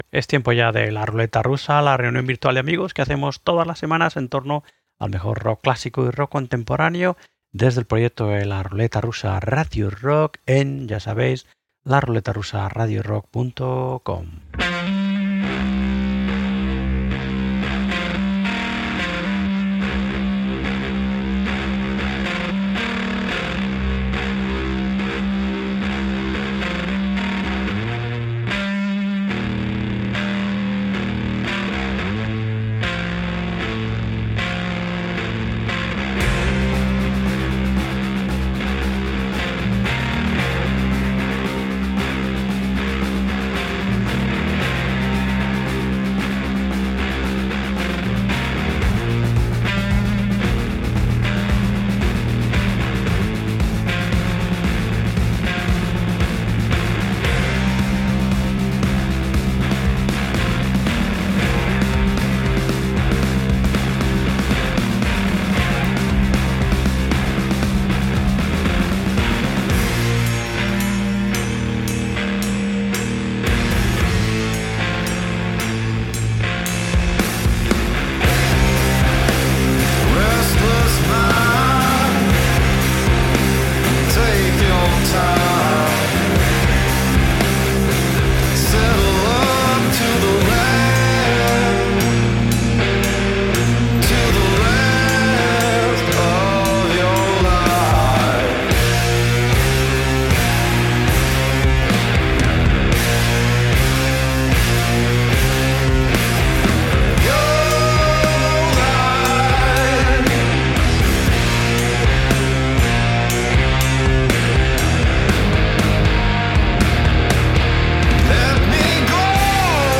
La Ruleta Rusa Radio Rock Author: La Ruleta Rusa Radio Rock Language: es Genres: Music , Music Commentary Contact email: Get it Feed URL: Get it iTunes ID: Get it Get all podcast data Listen Now...
En este número hemos escuchado y comentado los siguientes discos: